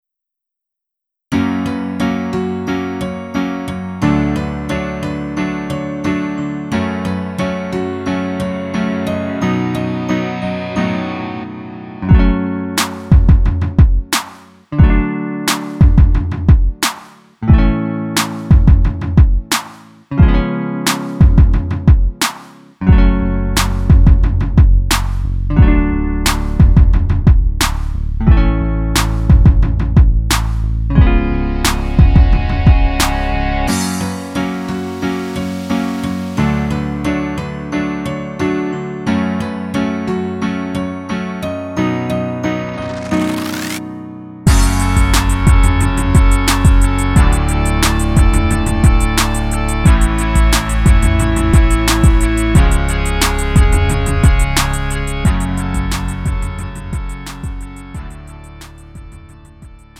음정 -1키 2:51
장르 가요 구분